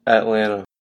Atlanta (/ætˈlæntə/
En-us-Atlanta-local.oga.mp3